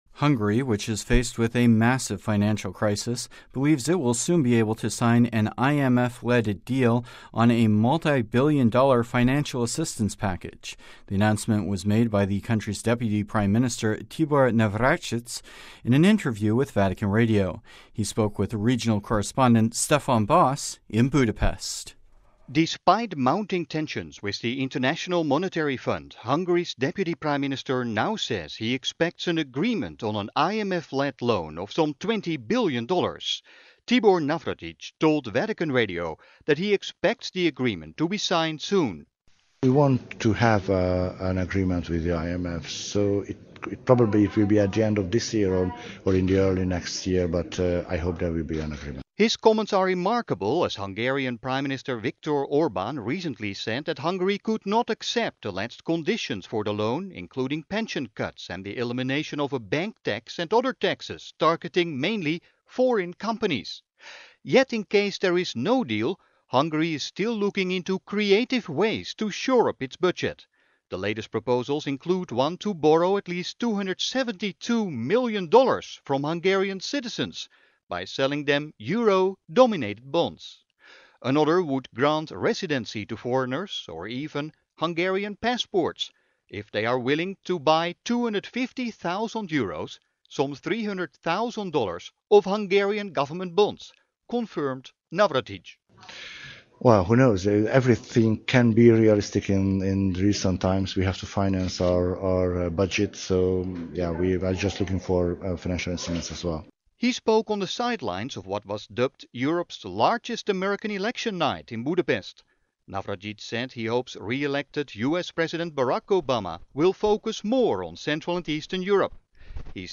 The announcement was made by the country's Deputy Prime Minister Tibor Navracsics in an exclusive interview with Vatican Radio.
Navracsics spoke on the sidelines of what was dubbed Europe's largest indoor American election, with some 1,500 guests attending the event in a luxurious Budapest hotel.